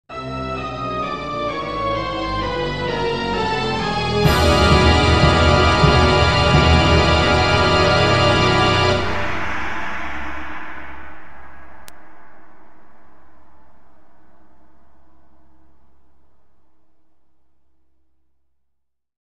the-end-sound-effect-free-download-cinematic-dramatic.mp3